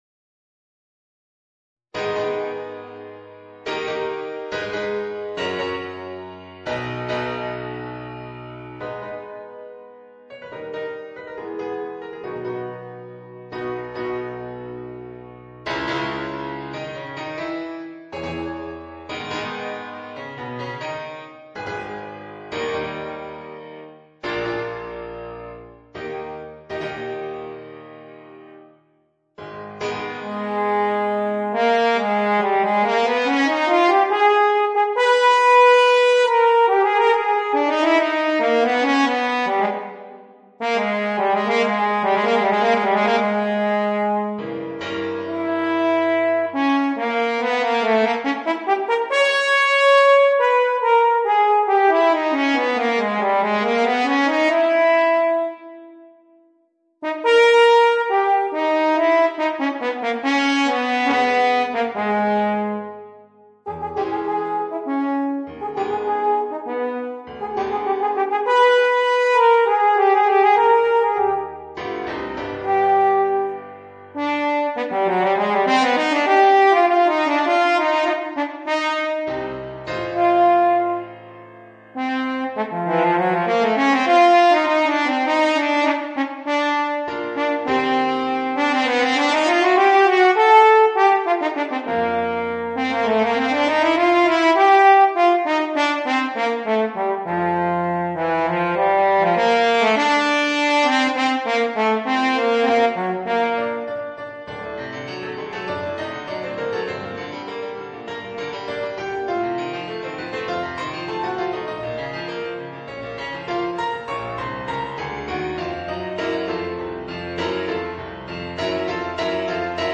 Es-Horn & Klavier